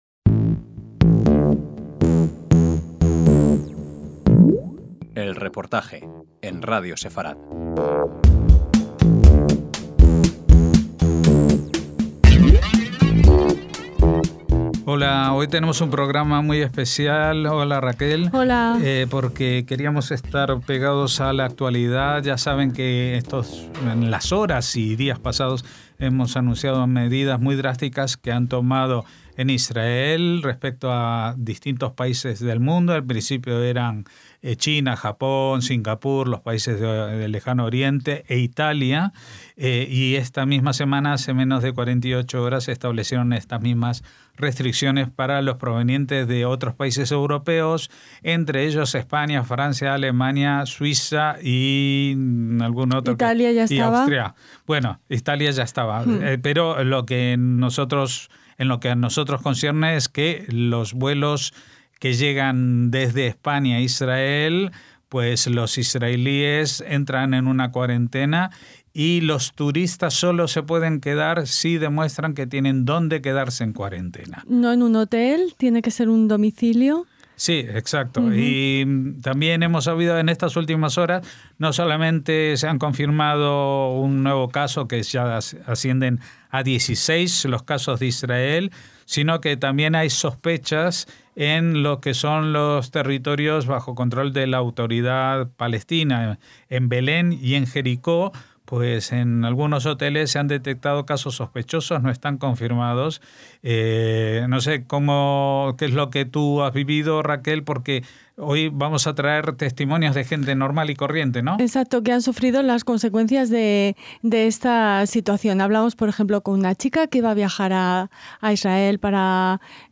España-Israel, coronavirus colateral: testimonios
EL REPORTAJE - Un chico israelí que vive en España y viajó el miércoles a Israel para celebrar Purim teniendo que regresar ya a Barcelona , una trabajadora sanitaria española en Israel que busca billete para volver , una invitada a una Bar Mitzvá en Jerusalén que no ha podido viajar desde Madrid y un mallorquín que cursa estudios en Tel Aviv al que su universidad ha pedido que no regrese, nos cuentan cómo el coronavirus ha afectado a sus viajes de ida o vuelta España/Israel, Israel/España... y a sus vidas.